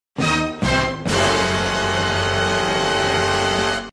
DUN DUN DUUUUN!!!